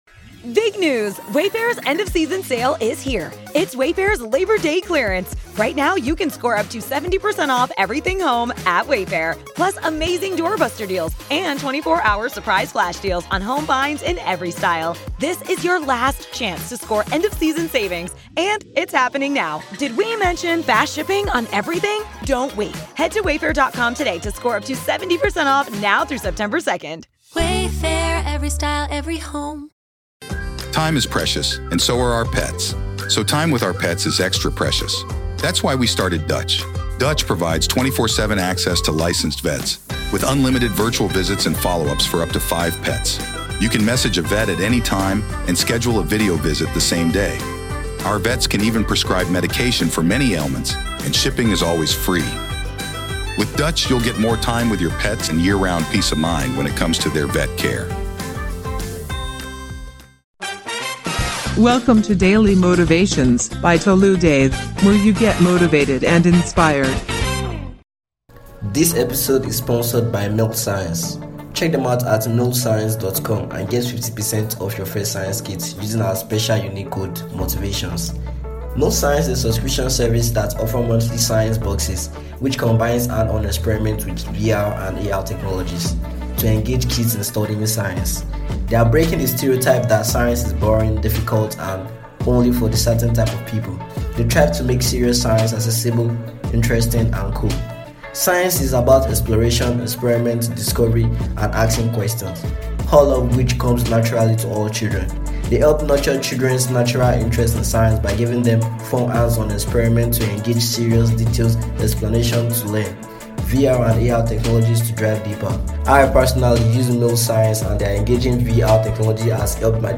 Speakers: Will Smith Tony Robbins Les Brown Jim Rohn Earl Nightingale